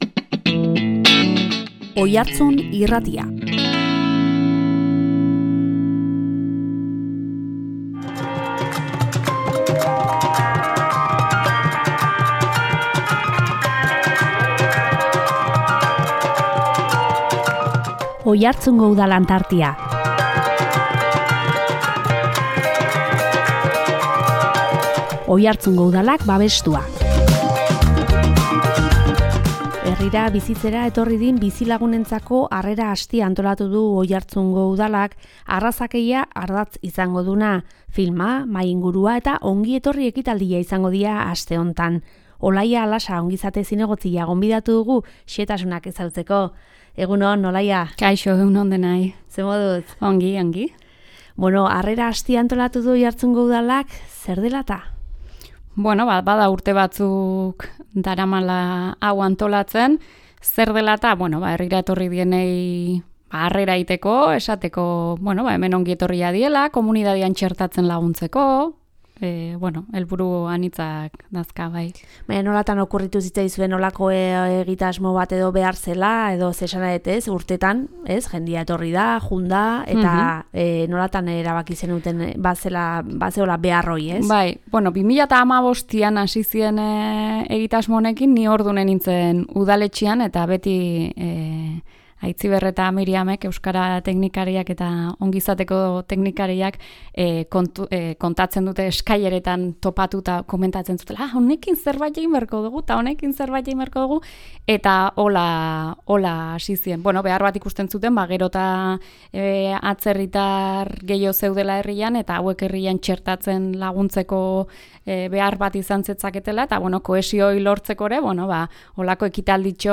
Herrira bizitzera etorri diren bizilagunentzako Harrera Astea antolatu du Oiartzungo Udalak, arrazakeria ardatz izango duena. Filma, mahai-ingurua eta ongietorri ekitaldia izango dira aste hontan. Olaia Lasa ongizate zinegotzia gonbidatu dugu xehetasunak ezagutzeko.